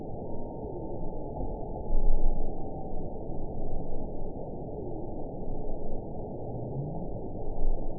event 921687 date 12/16/24 time 22:19:33 GMT (4 months, 2 weeks ago) score 8.22 location TSS-AB06 detected by nrw target species NRW annotations +NRW Spectrogram: Frequency (kHz) vs. Time (s) audio not available .wav